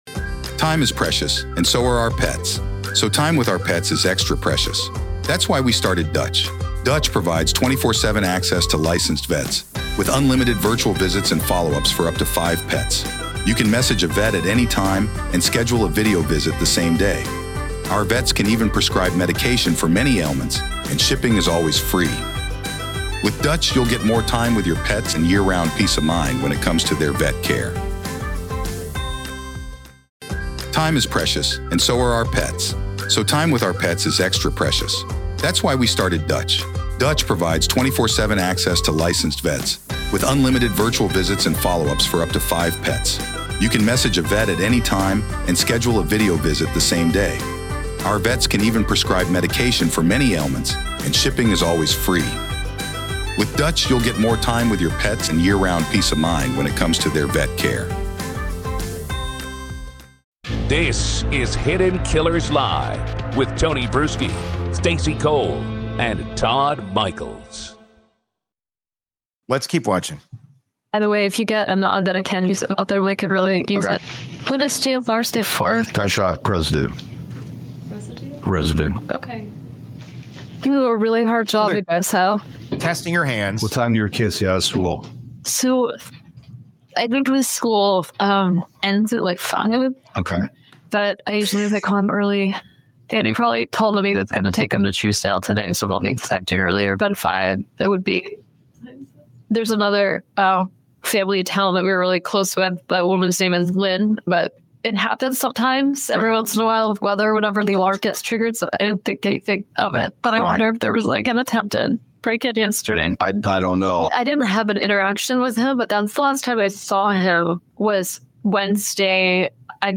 The team points out how her tone shifts depending on the question — at times defiant, at times almost childlike.
The hosts even share their own experiences being interrogated for unrelated reasons, showing just how intimidating the process can be.